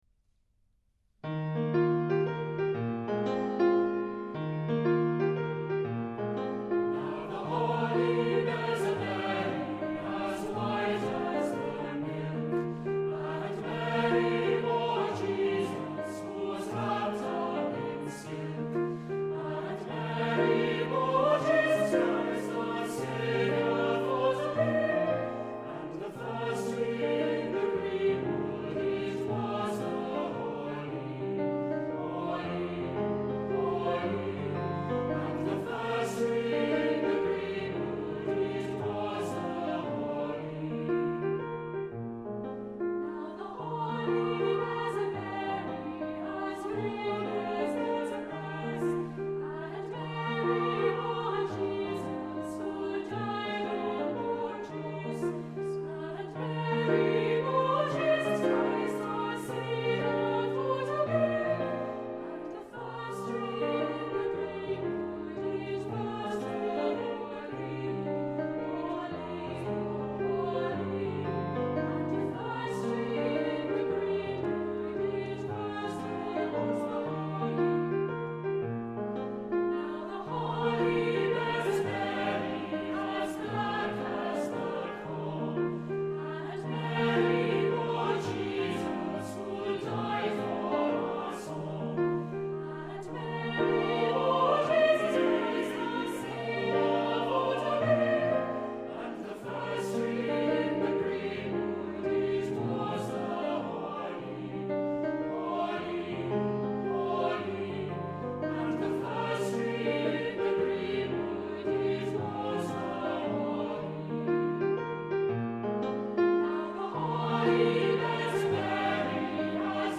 Recueil pour Chant/vocal/choeur